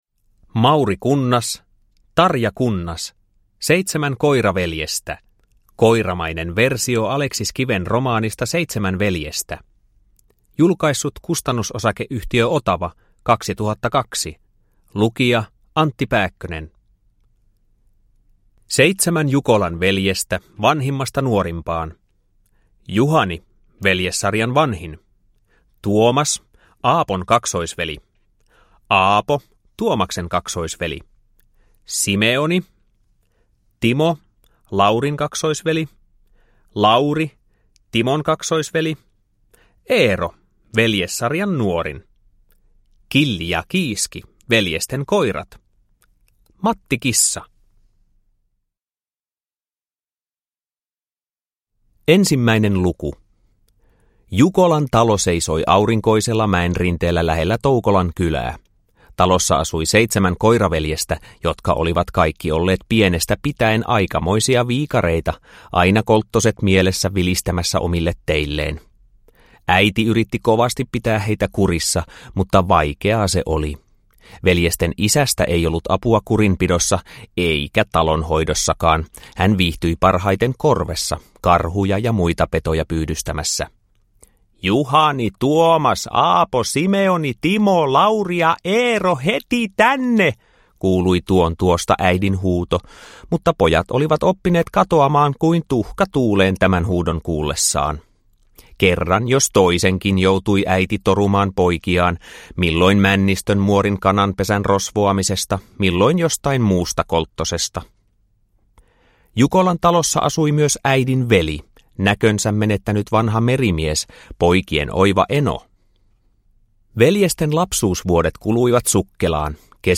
Seitsemän koiraveljestä – Ljudbok – Laddas ner
Uppläsare: Antti Pääkkönen